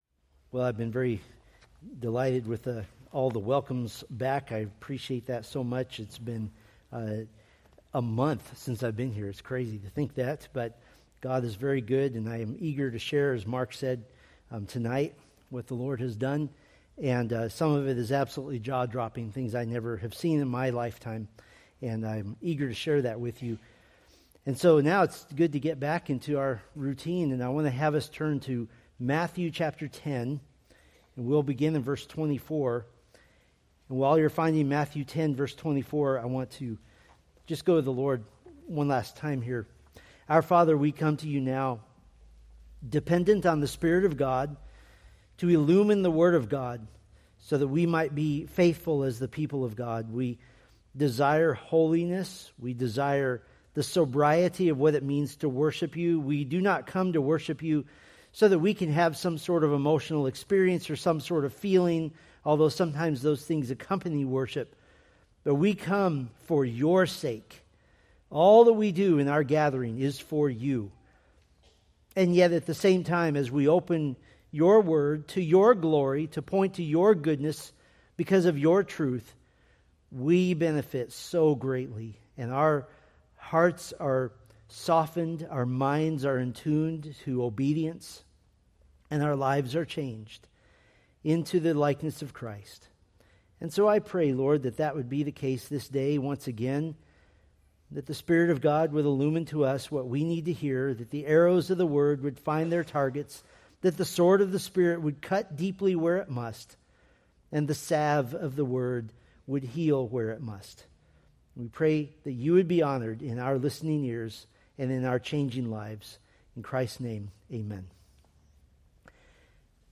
Preached June 8, 2025 from Matthew 10:24-33